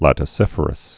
(lătĭ-sĭfər-əs)